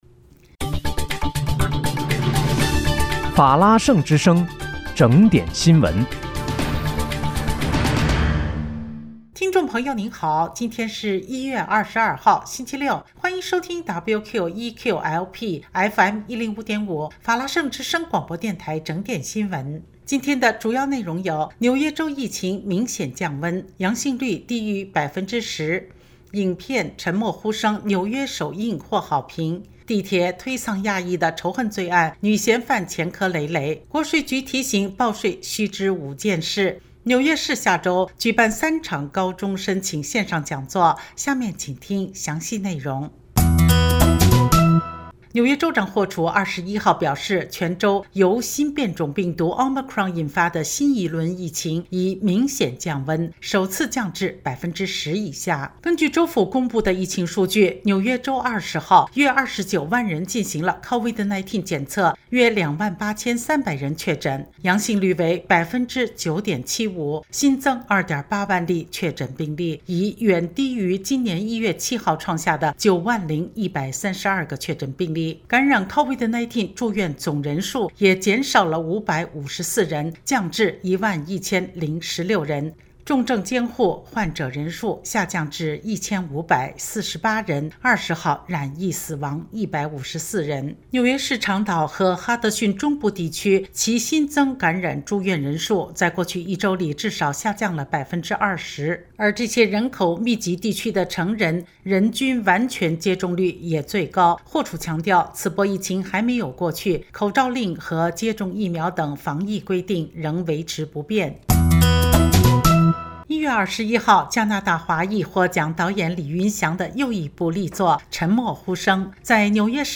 1月22日（星期六）纽约整点新闻